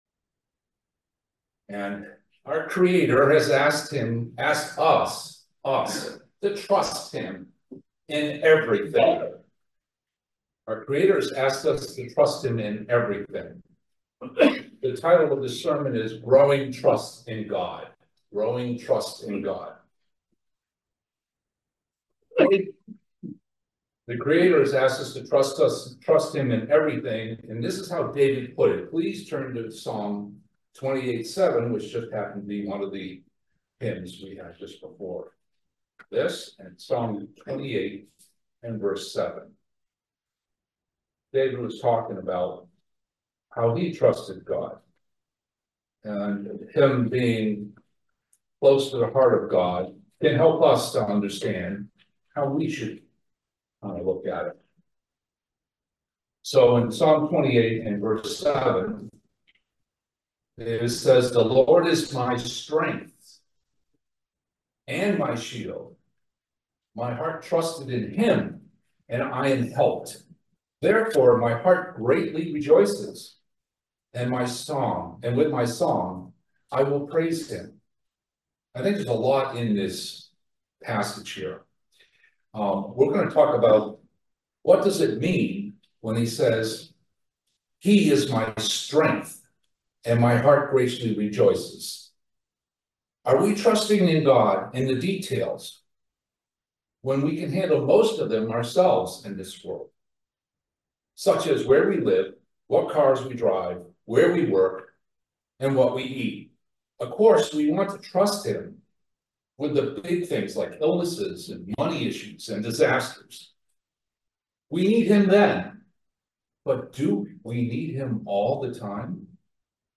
Join us for this very interesting video sermon on the subject of growing trust in God. Do we trust God?